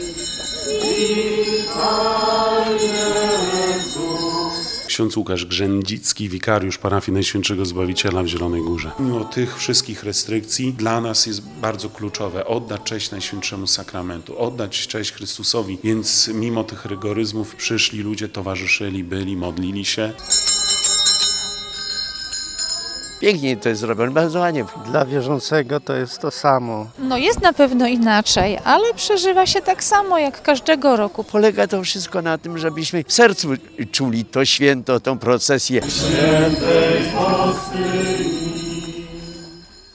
Zamiast corocznej procesji Bożego Ciała ulicami miasta, wierni przeszli wokół kościoła.